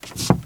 MISC Wood, Foot Scrape 02.wav